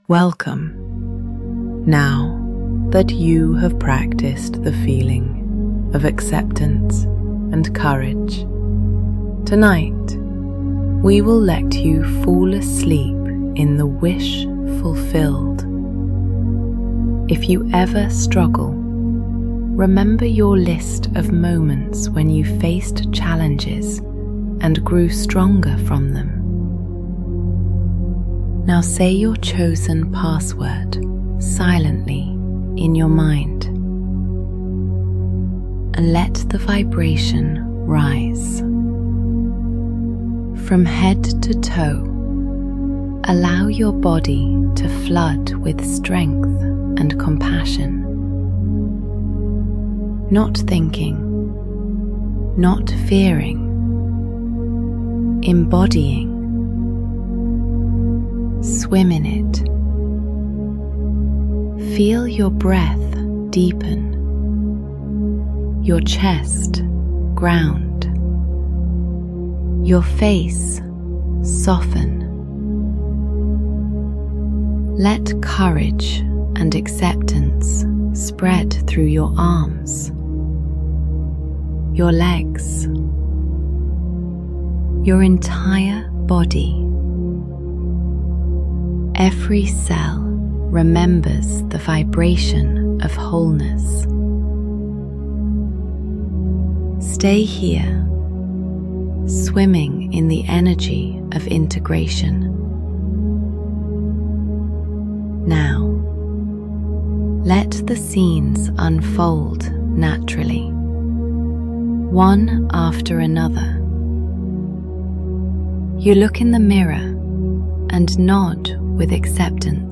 Female Voiced Meditation